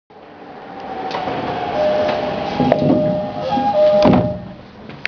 0・2000番台ドアチャイム
よくある３打点ですが、0番台の初期の車両のみドアが空気式だったため、ドアが動く音がするのが特徴でした。
door.WAV